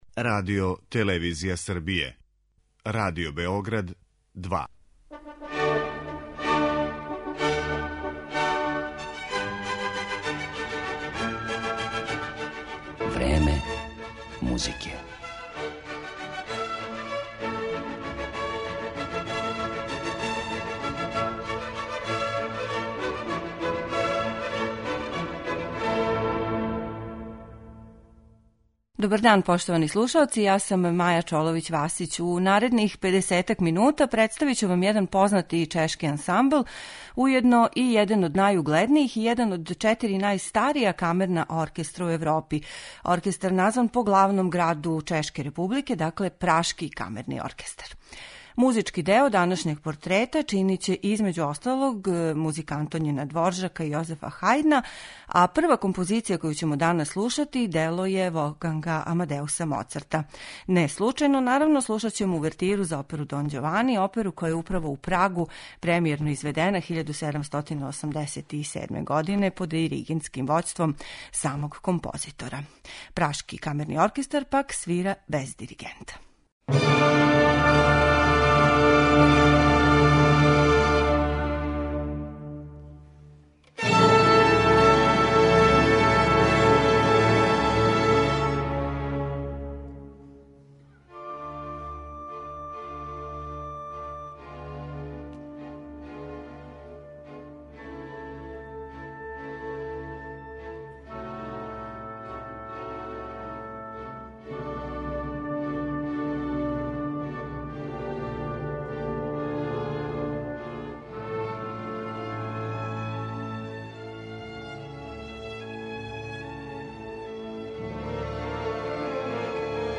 оркестру
фестивал класичне музике